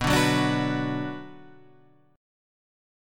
B Minor